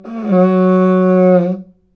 Index of /90_sSampleCDs/NorthStar - Global Instruments VOL-1/WND_AfrIvoryHorn/WND_AfrIvoryHorn